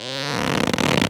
foley_leather_stretch_couch_chair_22.wav